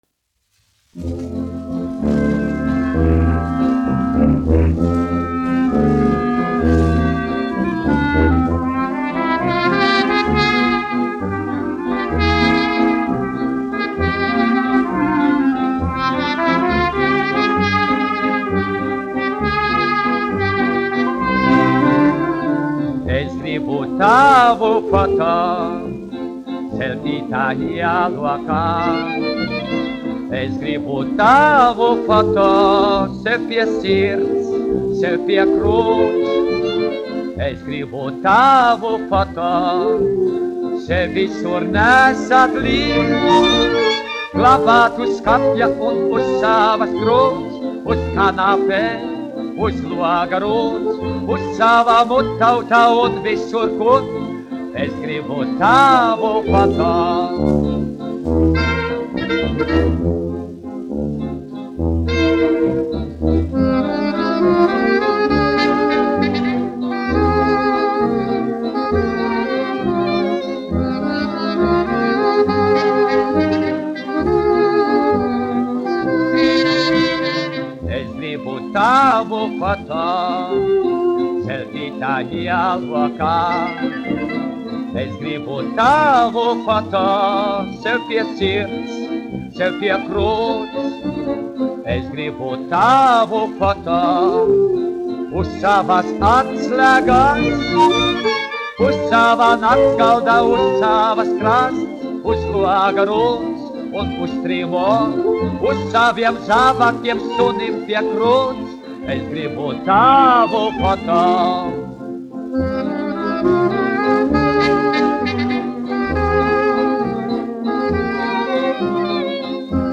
1 skpl. : analogs, 78 apgr/min, mono ; 25 cm
Operetes--Fragmenti
Skaņuplate